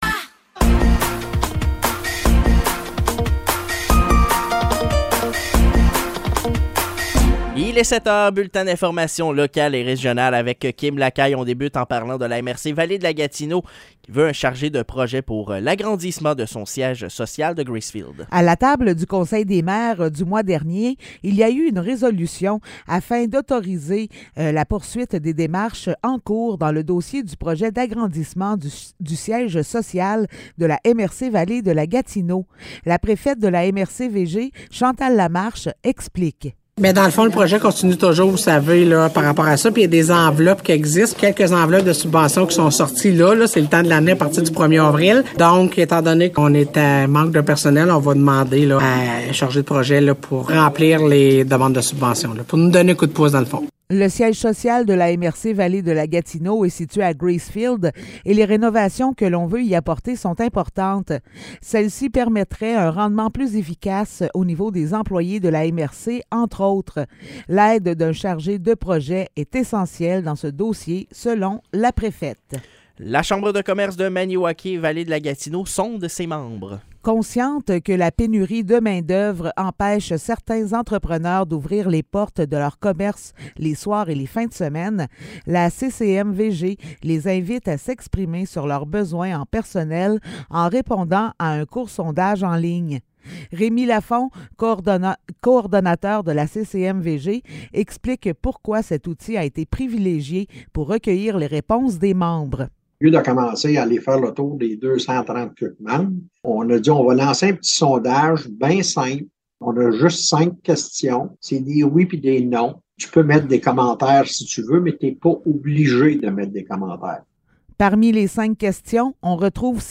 Nouvelles locales - 4 avril 2023 - 7 h